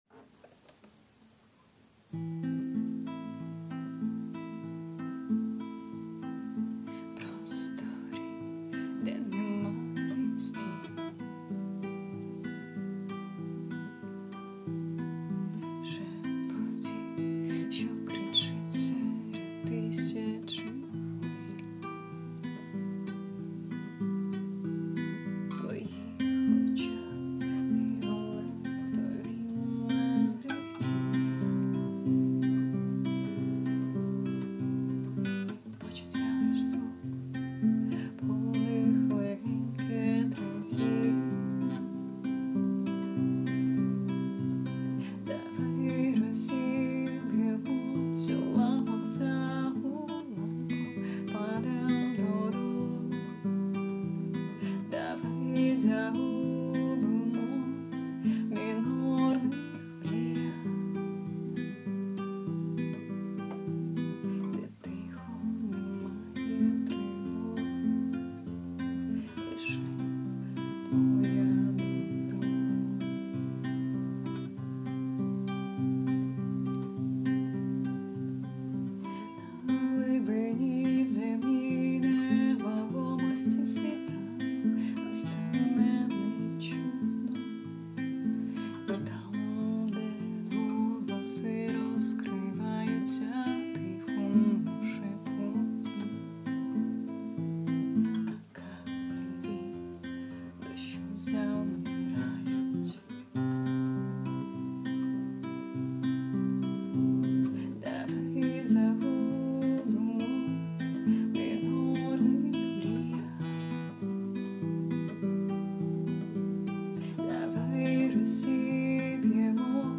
ТИП: Пісня
СТИЛЬОВІ ЖАНРИ: Ліричний
Дуже тендітна пісня...
Але не бійтесь співати трохи голосніше hi